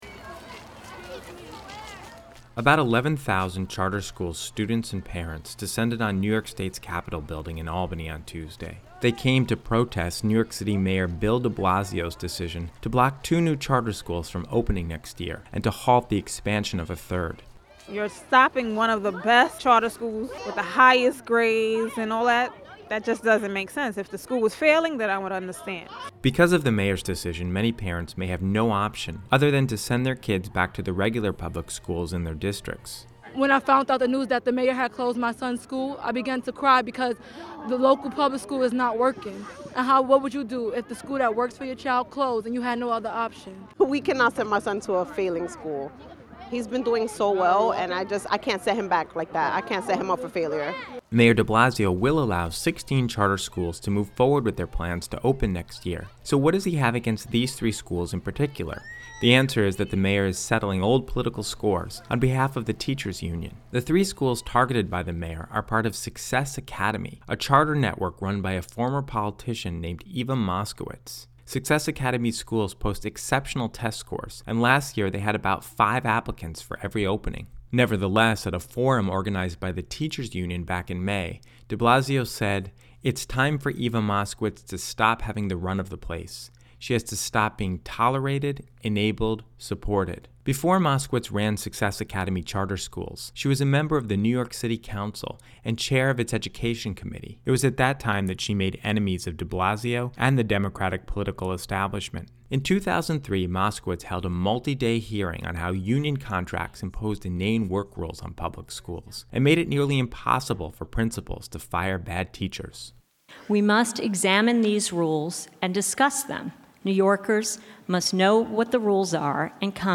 About 11,000 charter-school students and their parents descended on the state capitol building in Albany on Tuesday to protest New York City Mayor Bill de Blasio's decision to block two new charter schools from opening next year and to halt the expansion of a third.